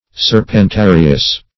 Serpentarius \Ser`pen*ta"ri*us\, n.[NL., fr. L. serpens